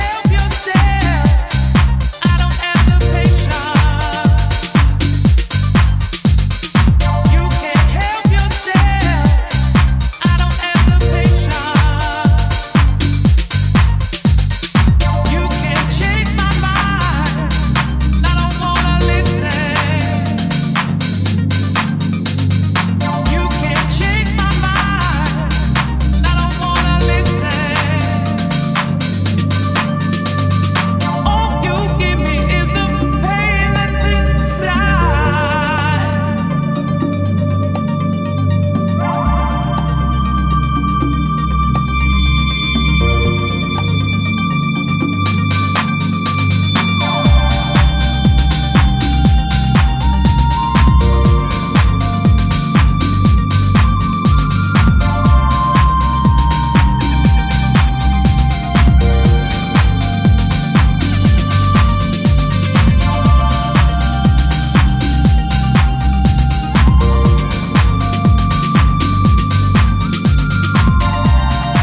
soulful, sensual, and deeply immersive